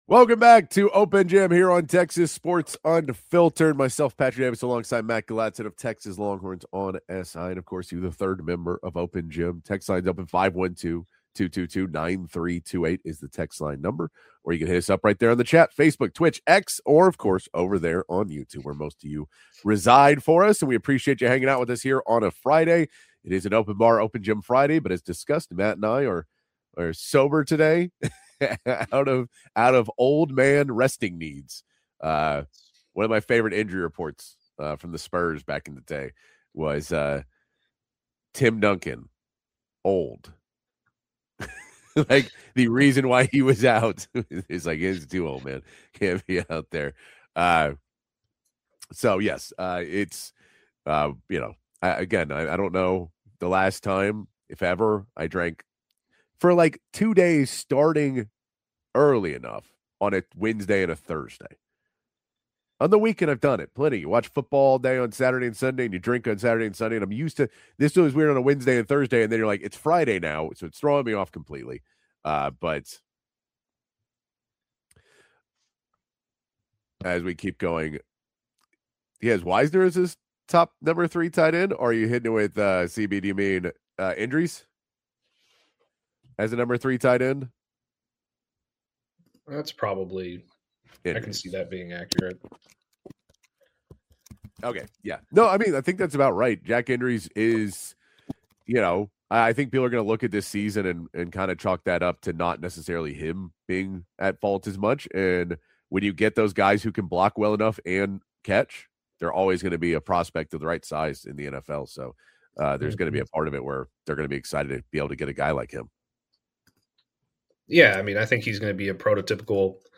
1 Encore presentation: A special broadcast from San Antonio 50:48